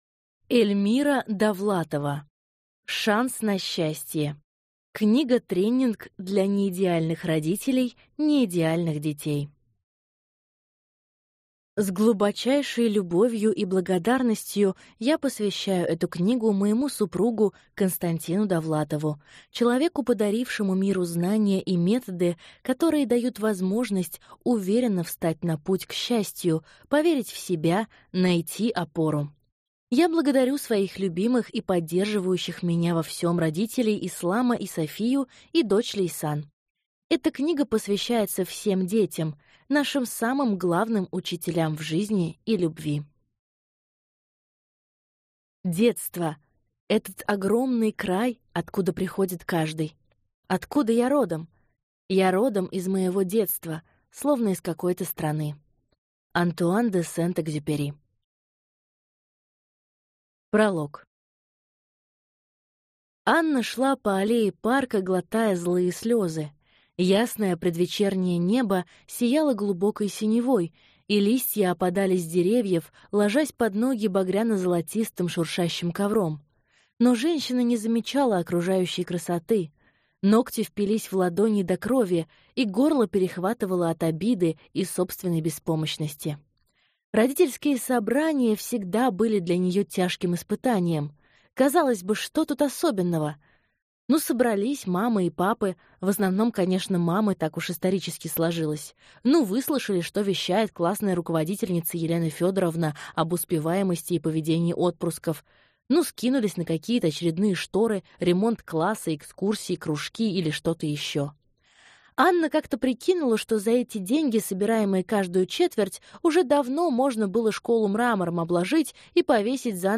Аудиокнига Шанс на счастье. Книга-тренинг для неидеальных родителей неидеальных детей | Библиотека аудиокниг